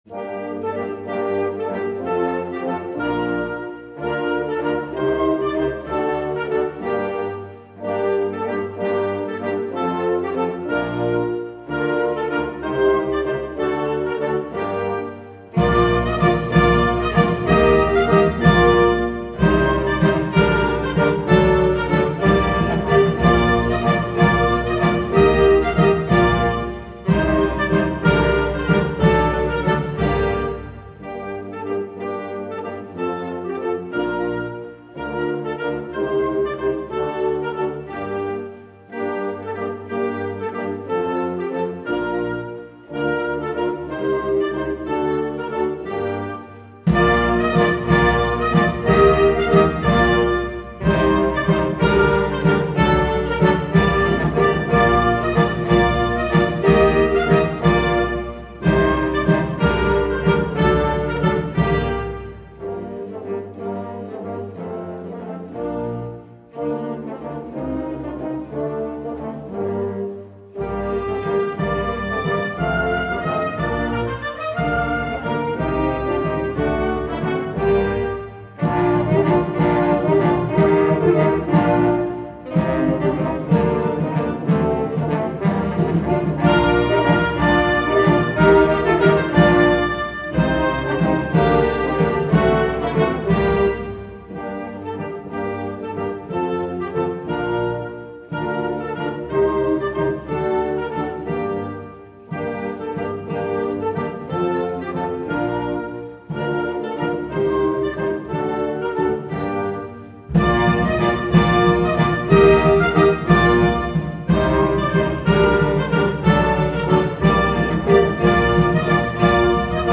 Suite per archi